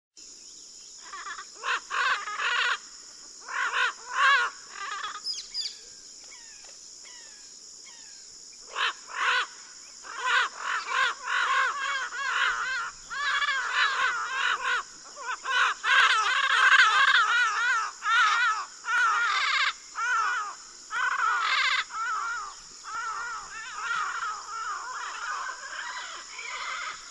Yellow-headed Parrot
Bird Sound
"kya-a-a-ah"
Yellow-headedParrot.mp3